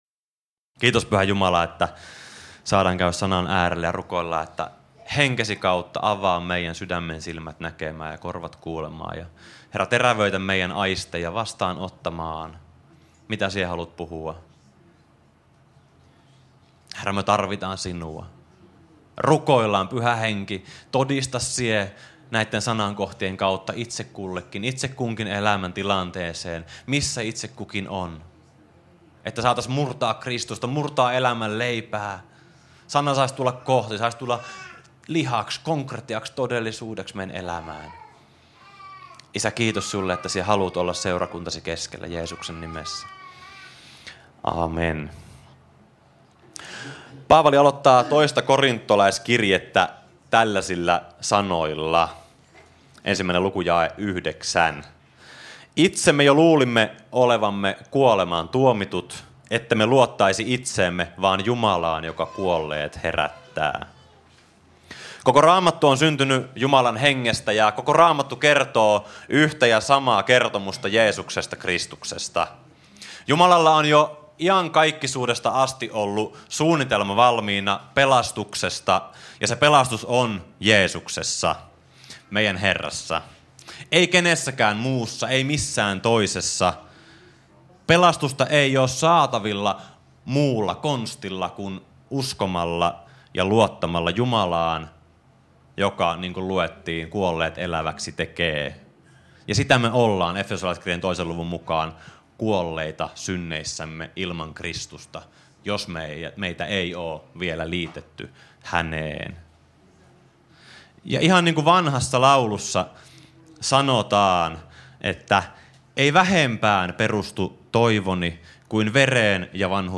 Jyväskylän Satamaseurakunnan opetuksista koottu podcast.